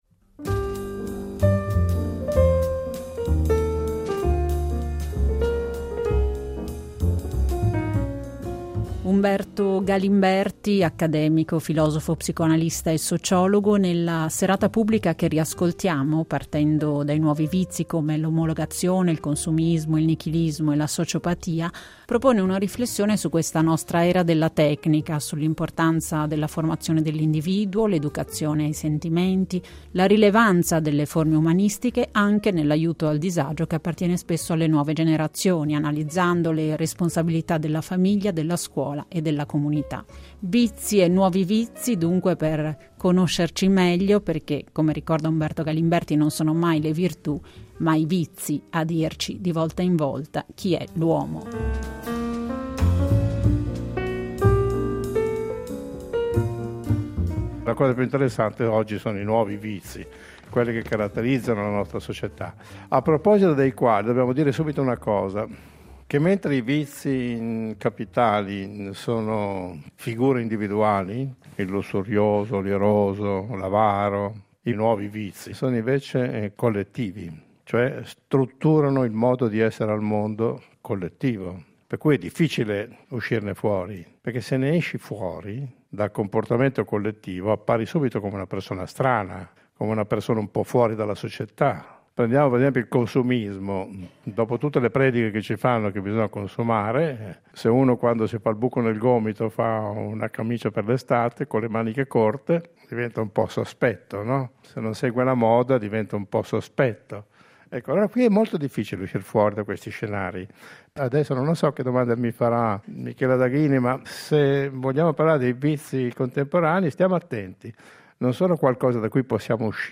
In questo "Laser" vi riproponiamo un riassunto della serata pubblica che si è tenuta nell’aprile scorso con il filosofo e psicoanalista Umberto Galimberti in un affollato Studio 2 della RSI. Una serata per riflettere sui vizi del contemporaneo, orientamenti collettivi e comportamenti della modernità legati al dissolvimento della personalità.